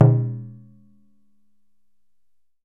DJUN DJUN05L.wav